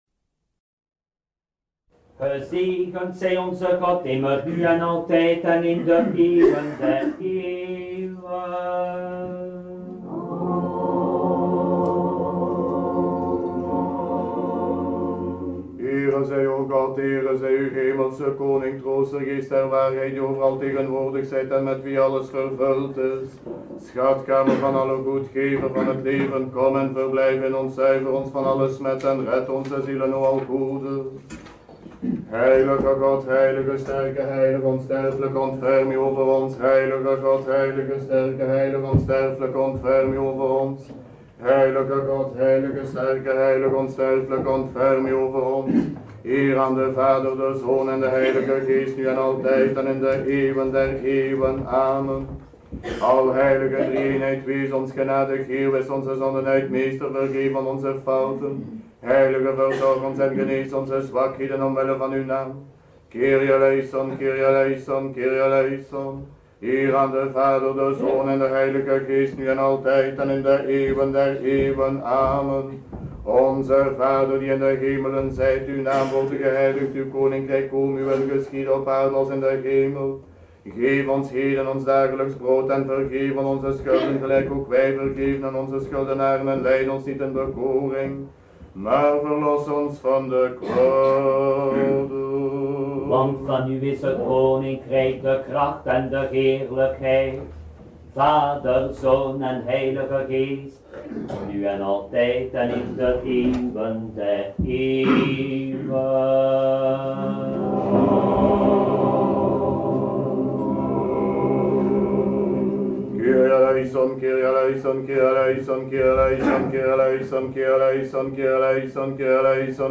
Deze audio-opname is van de Paasnacht, opgenomen in de schoot van de Orthodoxe Parochie van de Heilige Apostel Andreas te Gent in het jaar 1982.